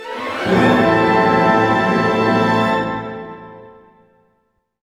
Index of /90_sSampleCDs/Roland LCDP08 Symphony Orchestra/ORC_Orch Gliss/ORC_Minor Gliss